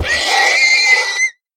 sounds / mob / horse / death.ogg